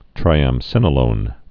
(trīăm-sĭnə-lōn)